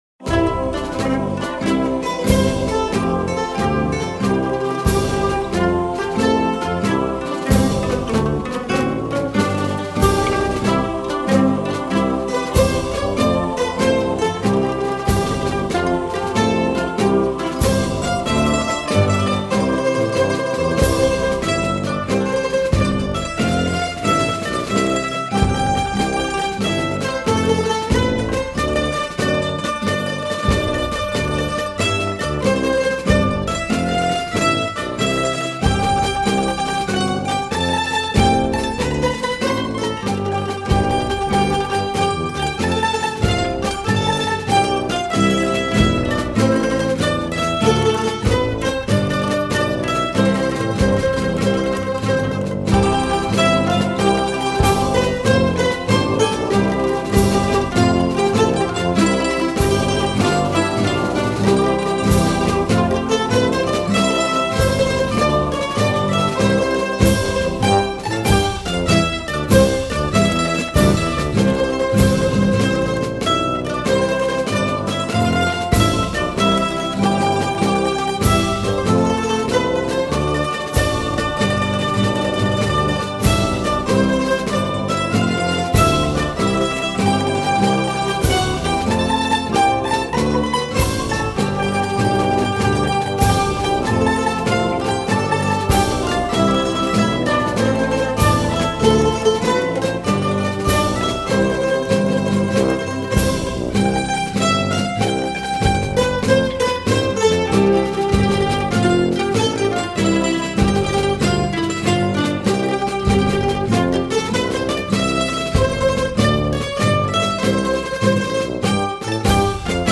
03:32:00   Valsa